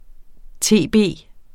tb forkortelse uofficiel, men almindelig form: TB Udtale [ ˈteˀˌbeˀ ] Betydninger = tuberkulose